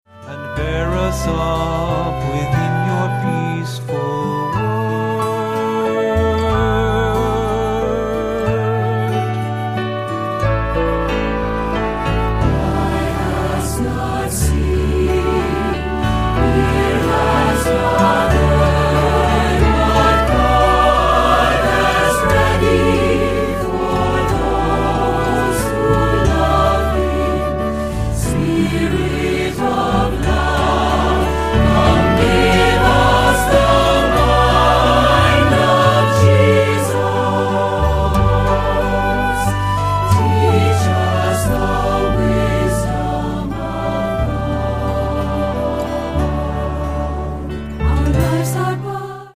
Accompaniment:      Keyboard, C Instrument I;C Instrument II
Music Category:      Christian